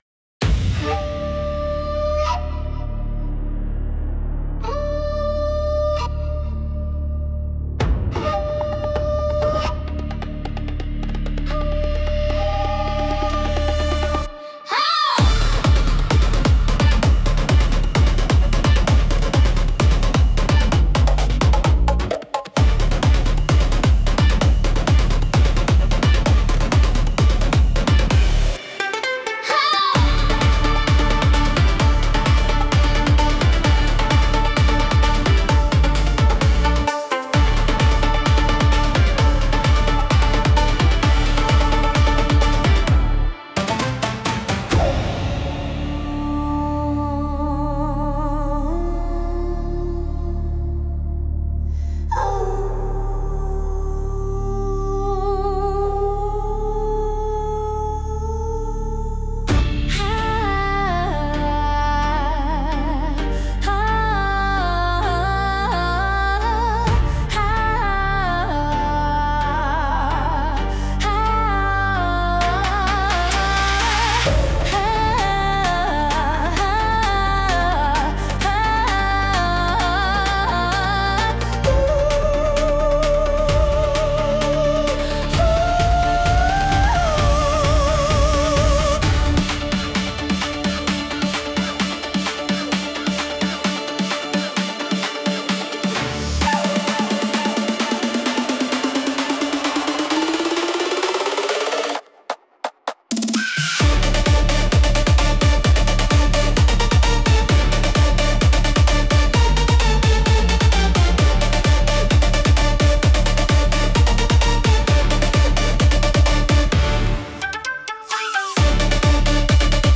creating a mysterious and powerful atmosphere.
和太鼓の重みと現代的なビートが融合した、スタイリッシュで力強い一曲。
和の雰囲気を感じさせる旋律に、女性コーラスが加わることで、 ミステリアスでクールなアクセントが生まれています。